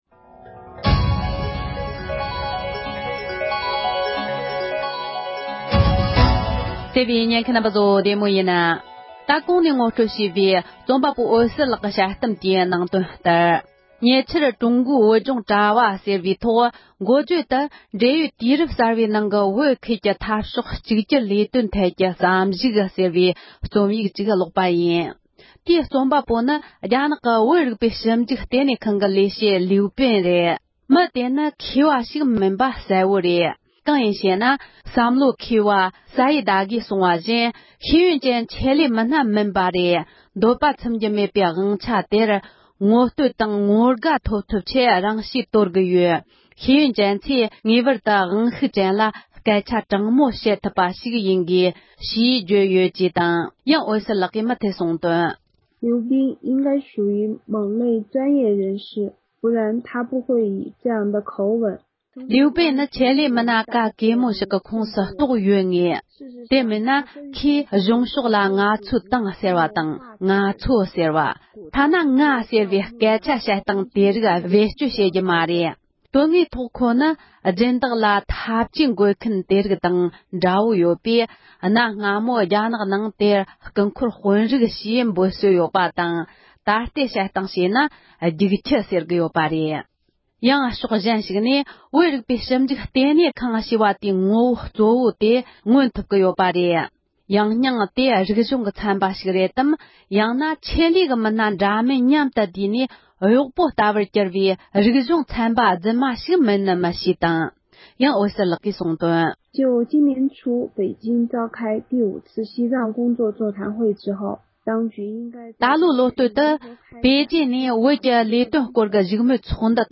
བོད་རིག་པའི་ཞིབ་འཇུག་ལྟེ་གནས་ཁང་གི་ཆེད་ལས་མི་སྣས་གུང་ཁྲན་ཚོགས་པར་ཐབས་ཇུས་མཁོ་འདོན་བྱས་པ་ཞེས་པའི་དཔྱད་གཏམ།